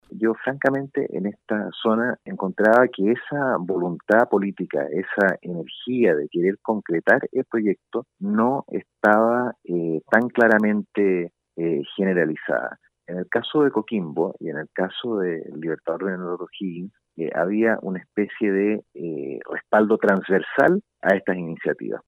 Anteriormente, el exintendente Raul Celis en conversación con Radio Bío Bío en Valparaíso acusó falta de compromiso político transversal para concretar la iniciativa, que se había transformado en una de las iniciativas emblemáticas de la administración de Sebastián Piñera en la región.